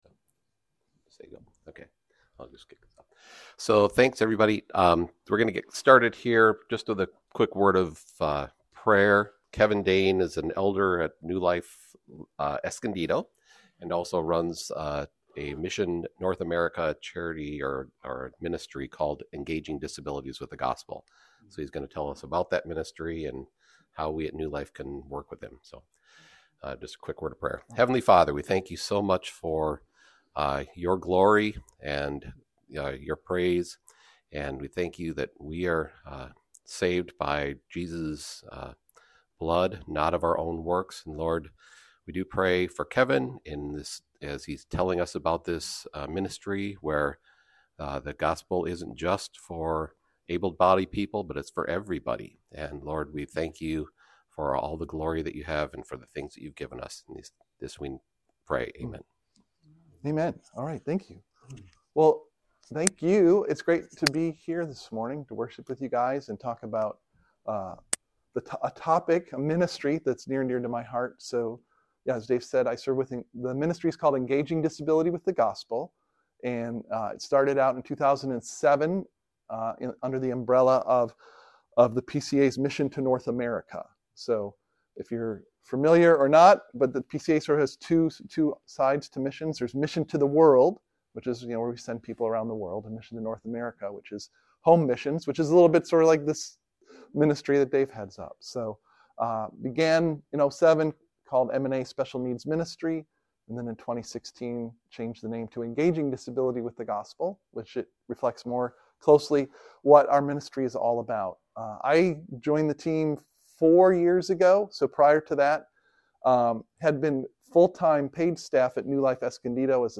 New Life Sunday School | New Life Presbyterian Church of La Mesa
Guest Speaker